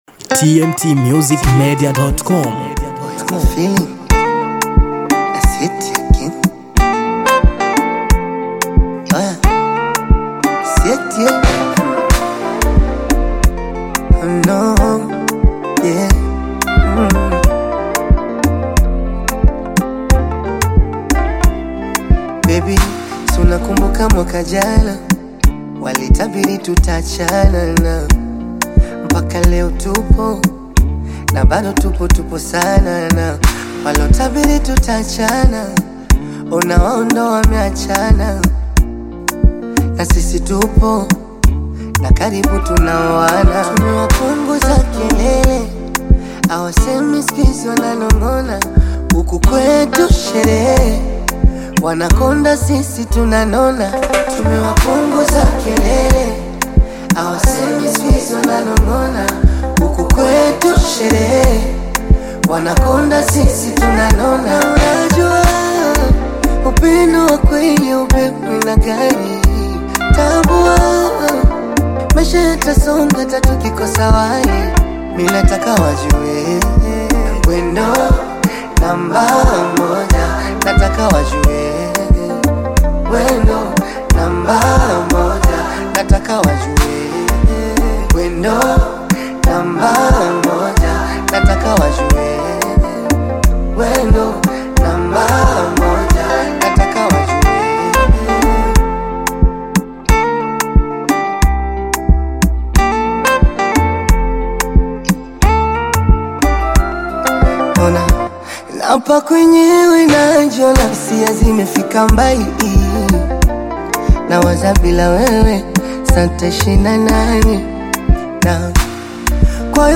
Tanzanian singer
love song